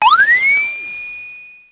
Index of /90_sSampleCDs/300 Drum Machines/Electro-Harmonix Spacedrum
Drum11.wav